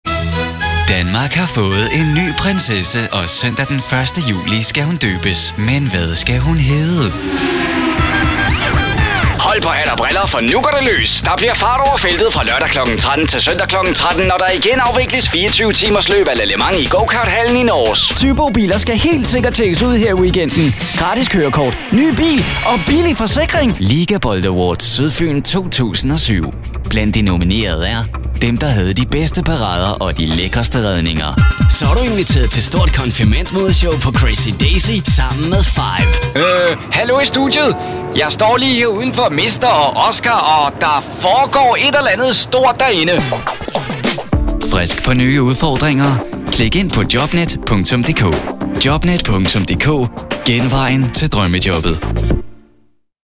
I specialize in the natural delivery, having a friendly and likeable voice the audience will feel comfortable with.
Sprechprobe: Werbung (Muttersprache):